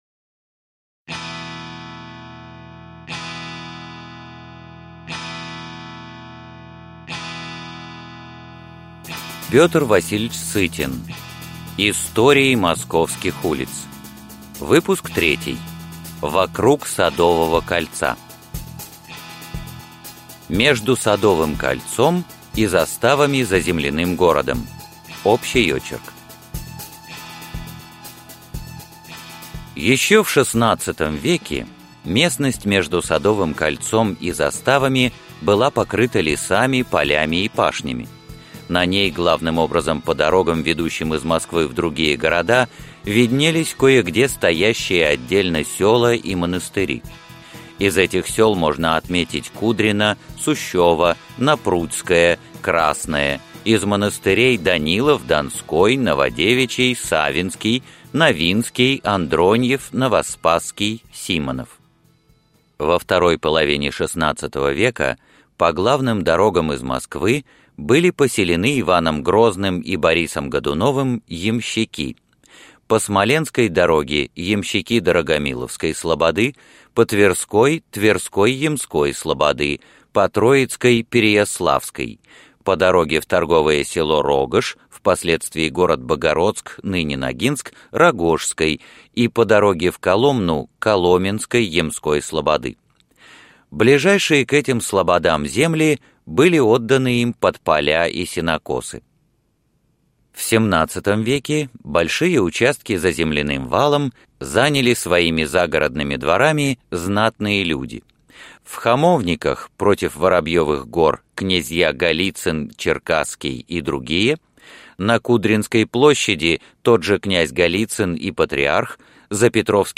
Аудиокнига Истории московских улиц. Выпуск 3 | Библиотека аудиокниг